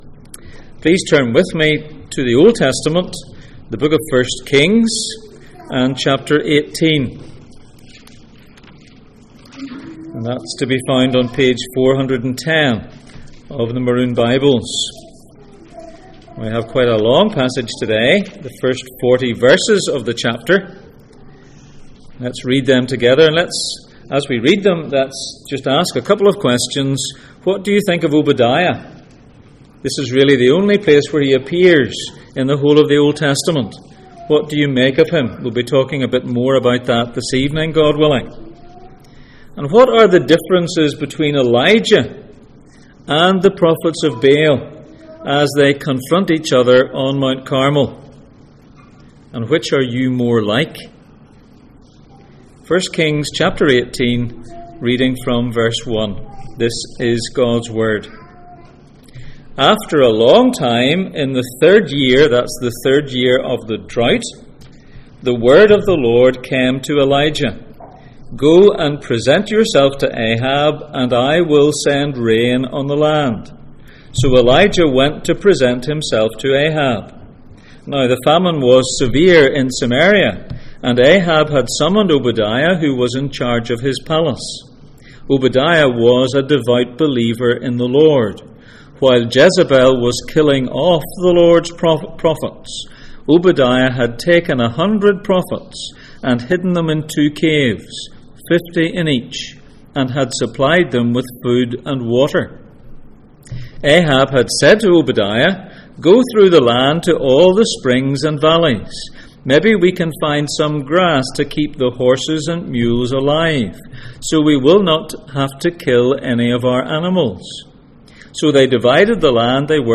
Elijah Passage: 1 Kings 18:1-40 Service Type: Sunday Morning %todo_render% « The Lord will provide